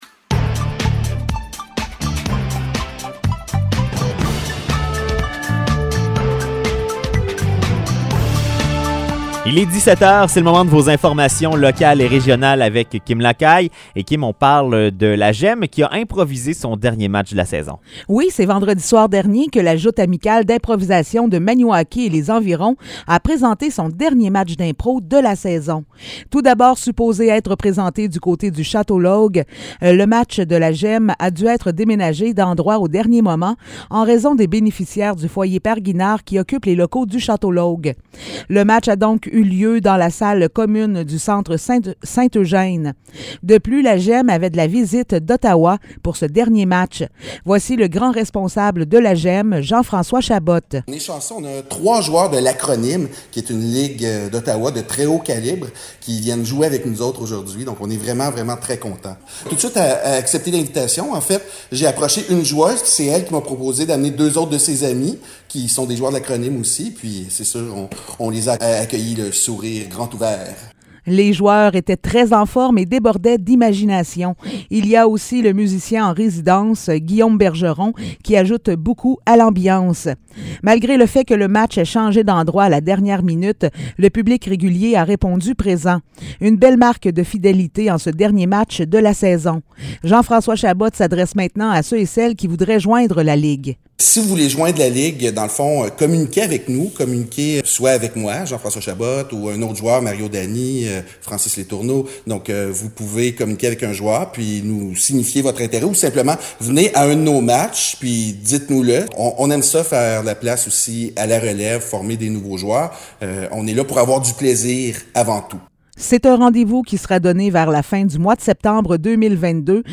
Nouvelles locales - 20 mai 2022 - 17 h